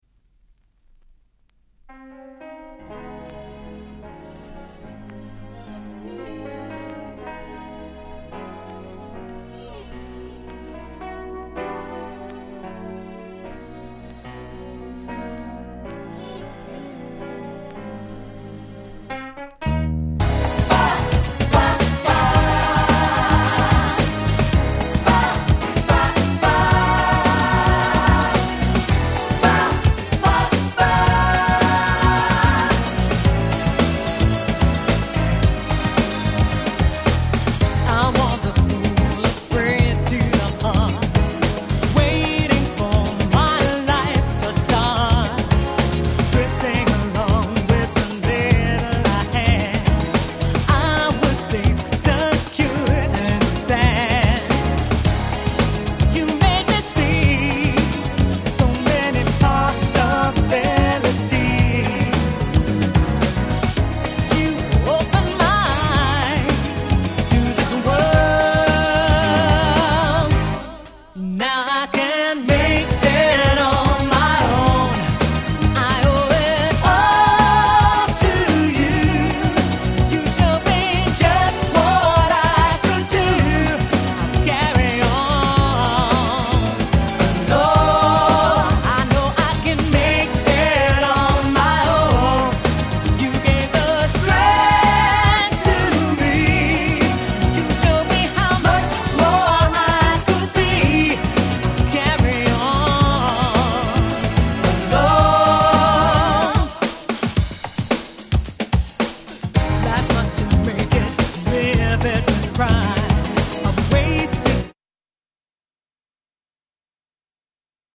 世界的大ヒットDanceナンバー。
Acid Jazz